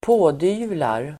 Ladda ner uttalet
Uttal: [²p'å:dy:vlar]